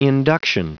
Prononciation du mot induction en anglais (fichier audio)
Prononciation du mot : induction